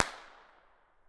pop2.wav